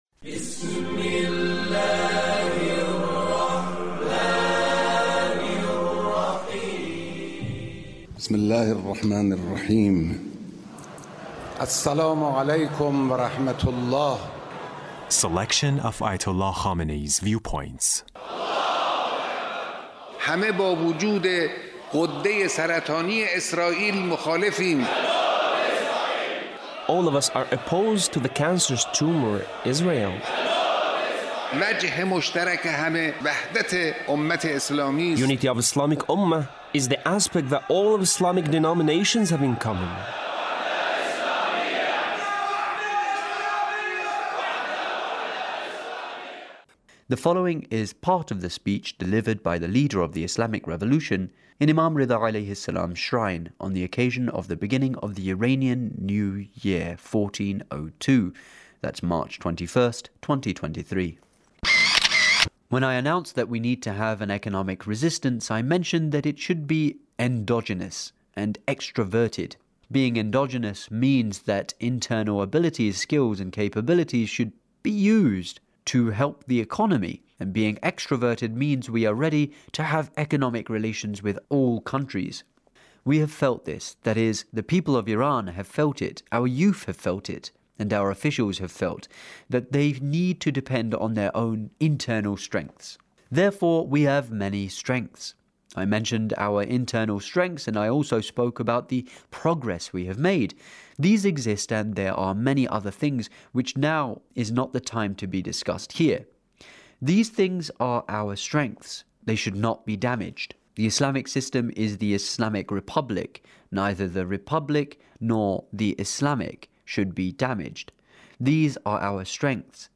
Leader's Speech (1676)